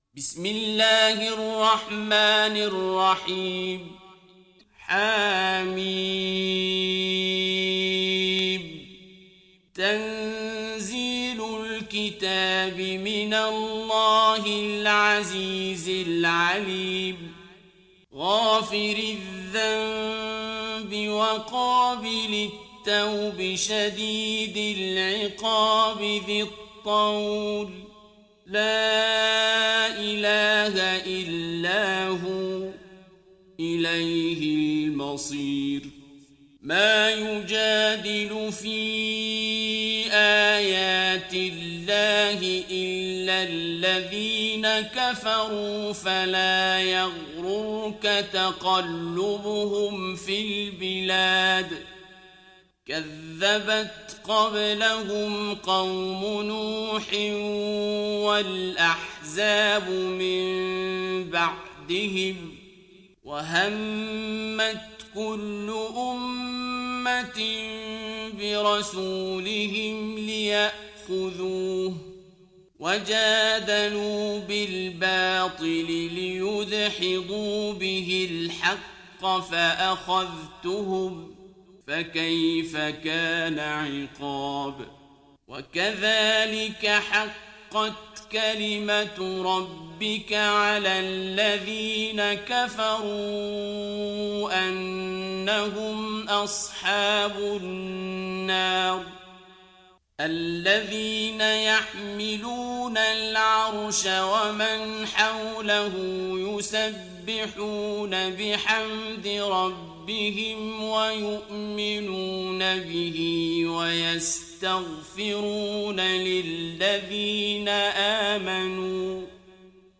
Surah Ghafir Download mp3 Abdul Basit Abd Alsamad Riwayat Hafs from Asim, Download Quran and listen mp3 full direct links